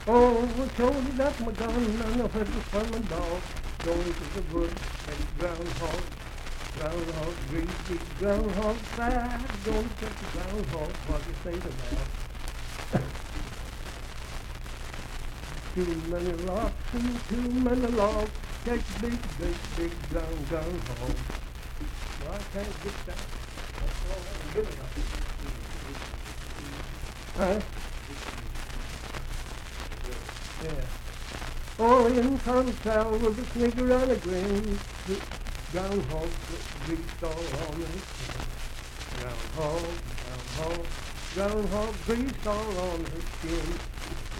Unaccompanied vocal music
in Mount Storm, W.V.
Dance, Game, and Party Songs
Voice (sung)
Grant County (W. Va.)